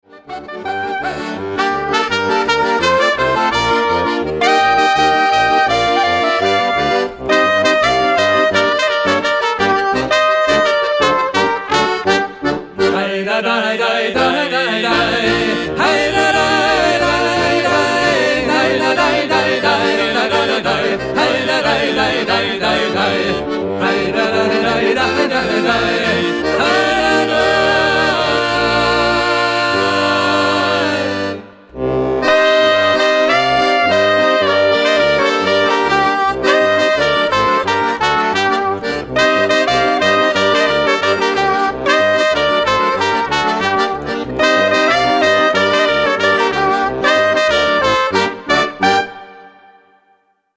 altem, mündlich überliefertem Volksliedgut aus Osteuropa